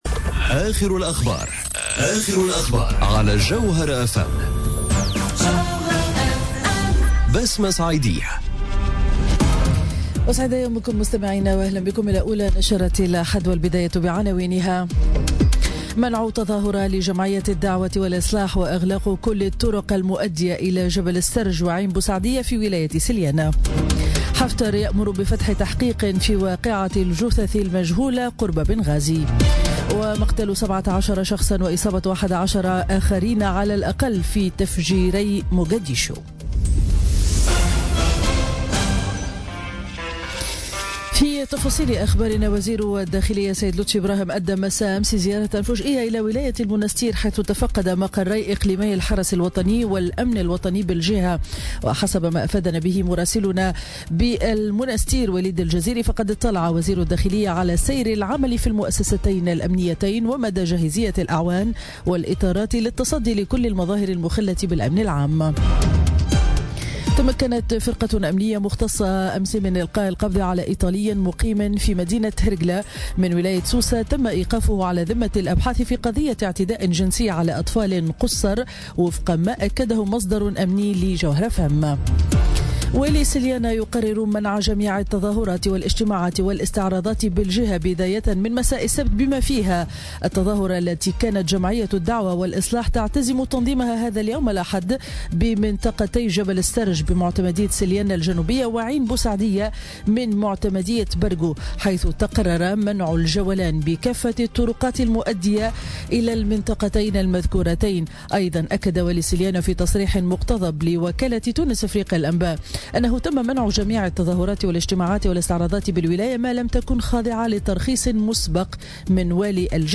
نشرة أخبار السابعة صباحا ليوم الأحد 29 أكتوبر 2017